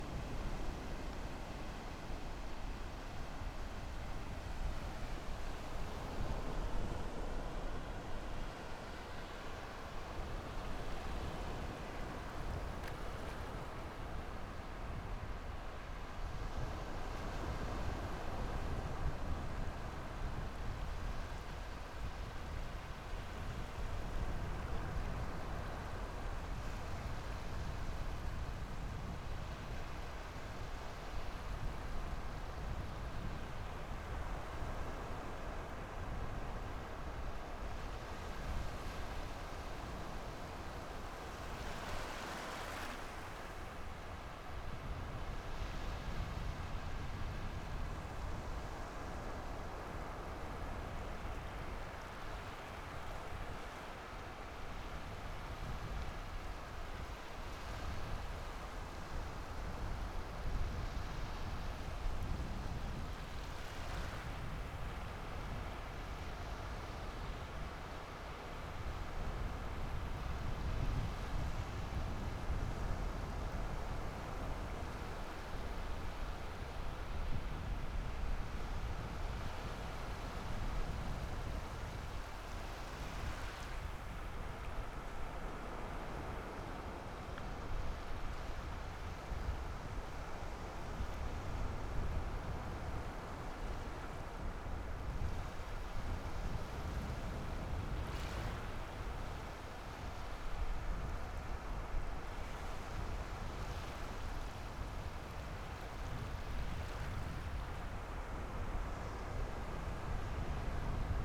Field Recording
Beach - Summer 2024 (Newport, Rhode Island, USA)